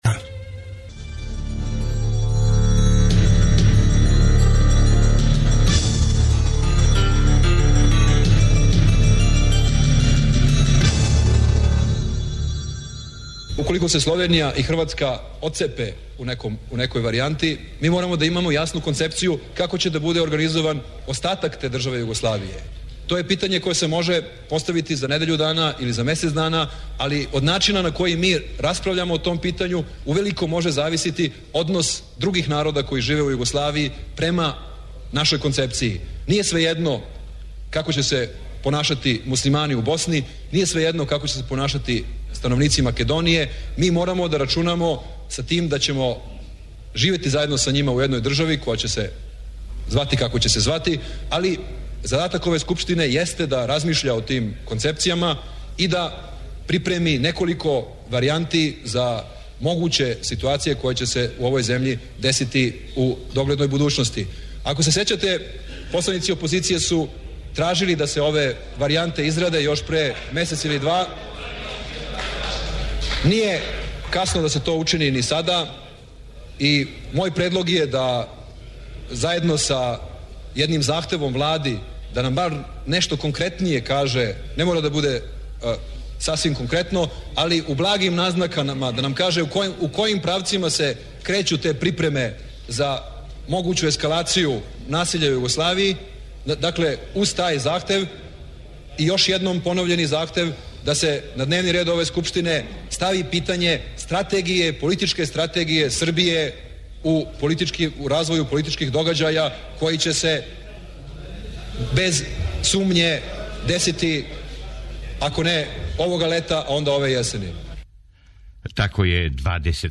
Присетићемо се кључних догађаја у деценијама иза нас који су се збили крајем јуна и чути радио записе који су те догађаје пратили.
Из историје СФРЈ слушамо репортажу о пријему спортиста код Тита, 23. јуна 1971., а из периода распада исте државе подсећамо на проглашење независности Словеније и Хрватске 25. јуна 1991. и заседање Скупштине Србије тим поводом дан касније.